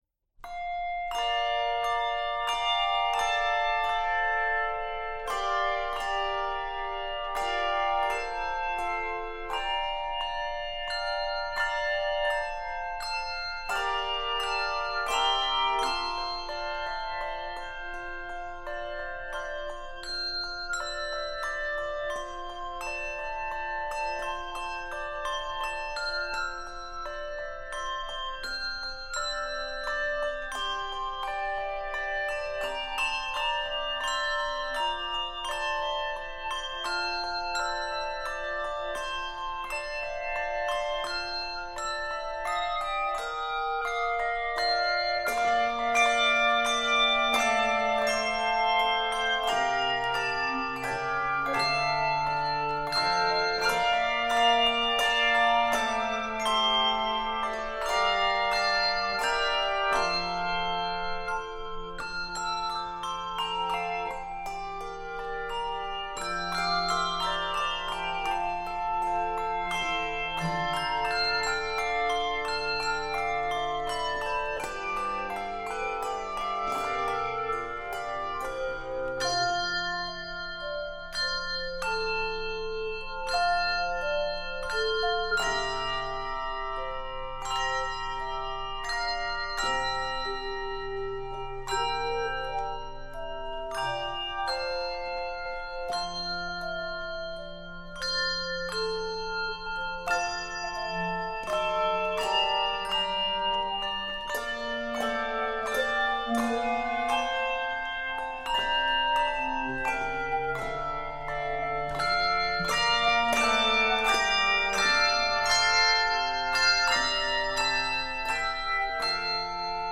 Keys of F Major and Bb Major.